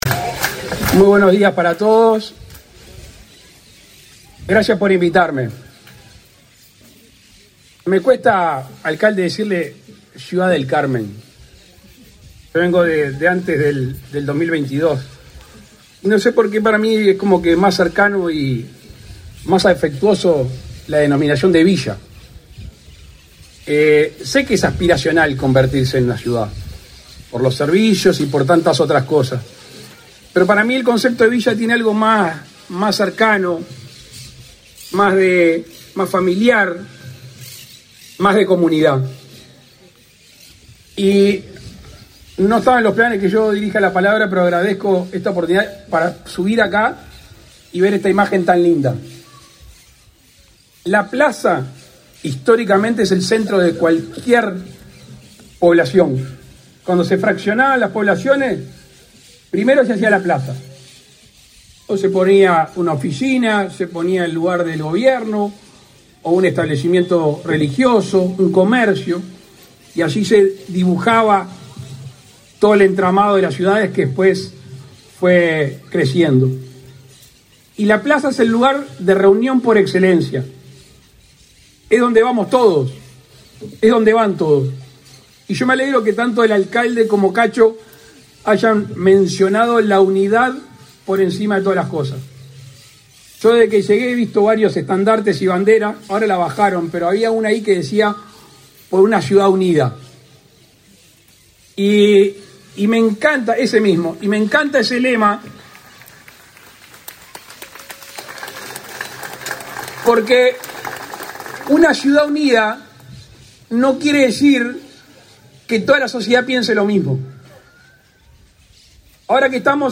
Palabras del presidente de la República, Luis Lacalle Pou
El presidente de la República, Luis Lacalle Pou, participó, este 10 de junio, en la celebración de los 150 años de Ciudad del Carmen, en el